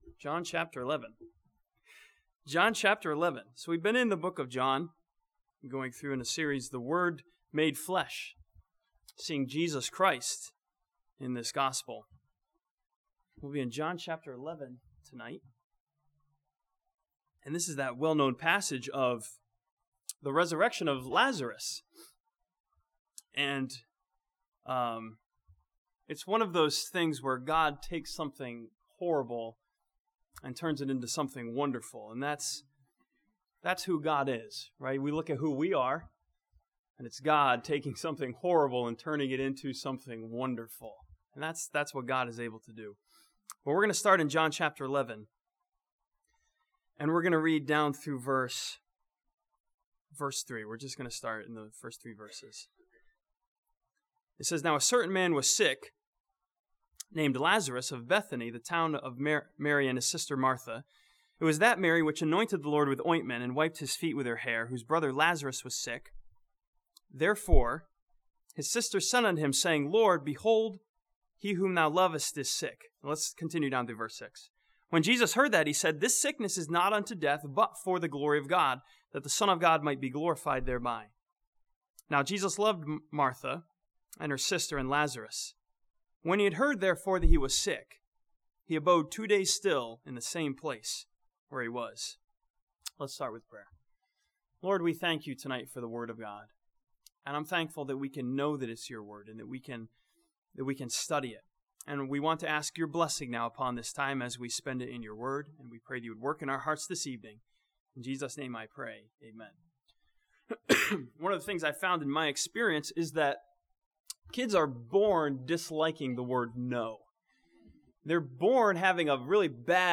This sermon from John chapter 11 encourages believers with four lesson to remember when God says 'no'.